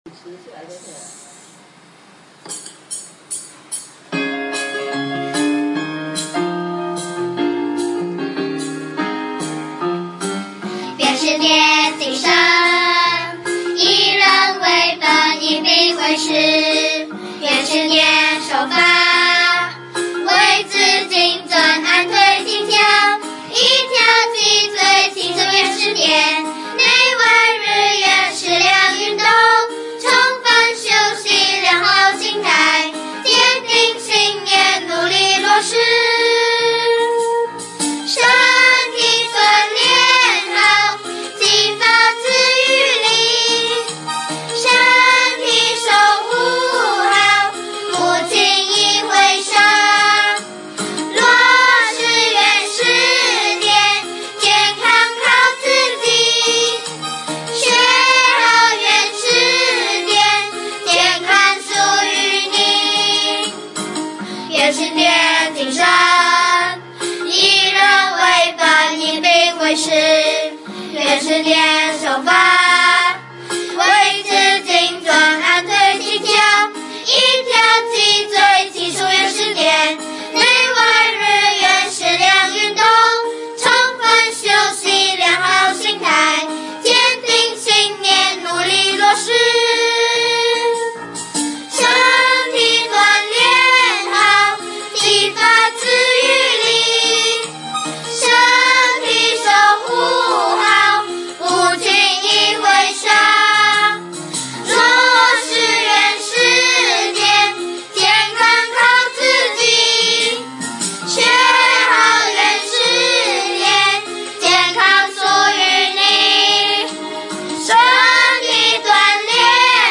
作曲、编曲、弹奏: 发心学员
主唱: 2017年马来西亚第一届原始点儿童一日学习营之《未来曙光》组长合唱